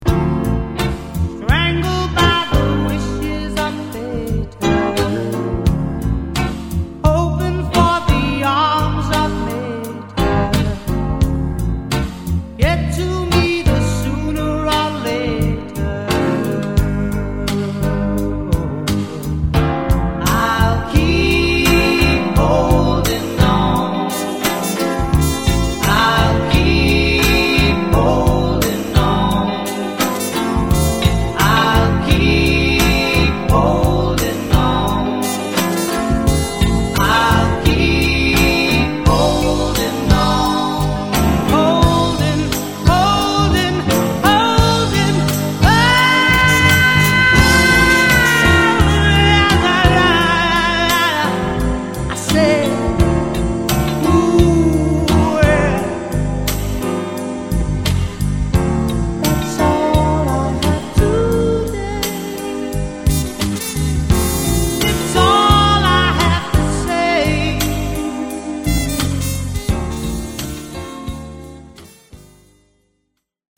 Forse il brano che ci piace di più è una ballad malinconica